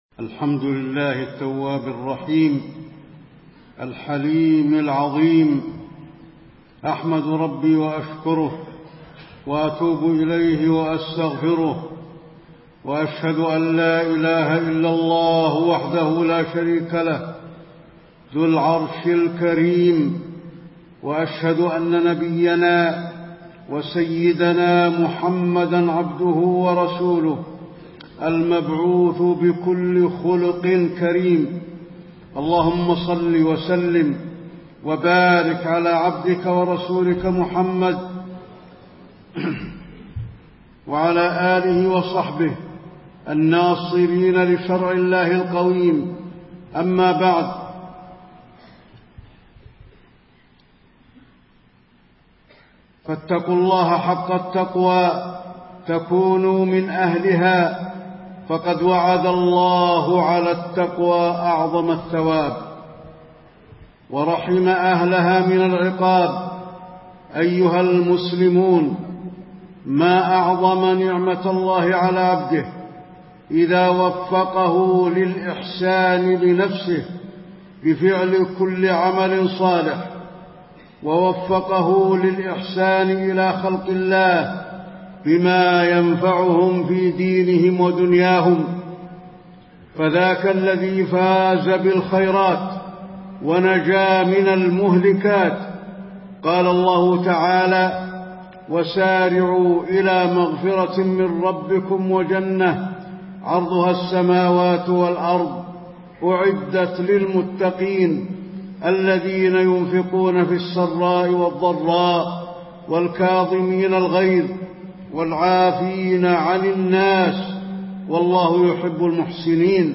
تاريخ النشر ٧ ذو القعدة ١٤٣٤ هـ المكان: المسجد النبوي الشيخ: فضيلة الشيخ د. علي بن عبدالرحمن الحذيفي فضيلة الشيخ د. علي بن عبدالرحمن الحذيفي خلق الرحمة